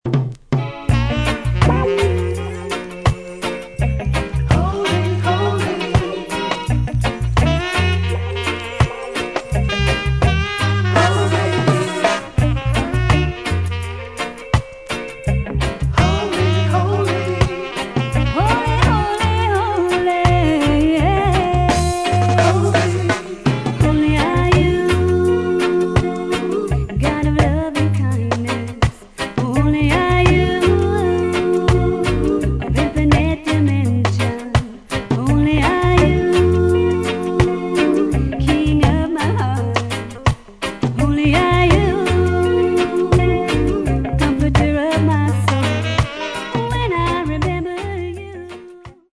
Recorded: Anchor Studio's Kgn.JA